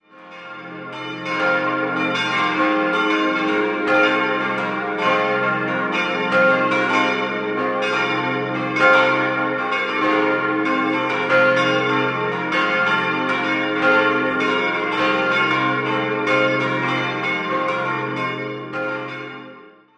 5-stimmiges Westminster-Geläute: c'-f'-g'-a'-c'' Die Glocken bilden das letzte Geläut, das die Gießerei Karl Czudnochowsky im Jahr 1970 hergestellt hat.